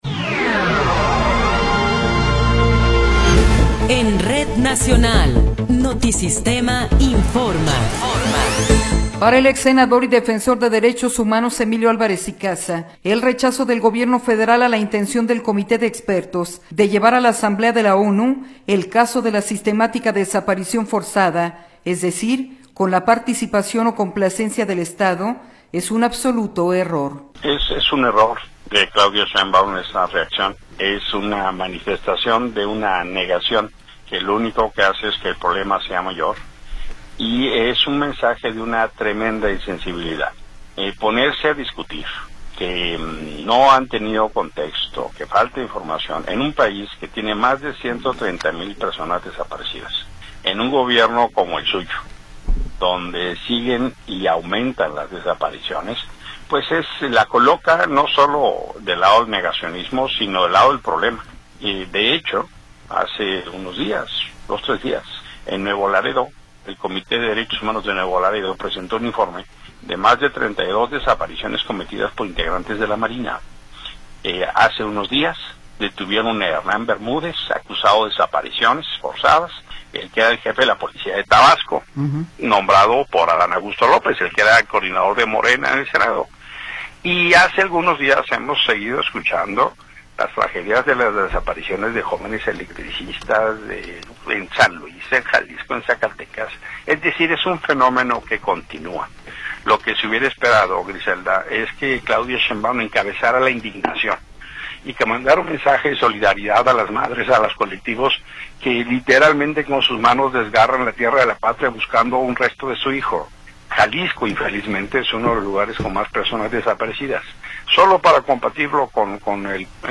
Noticiero 11 hrs. – 19 de Abril de 2026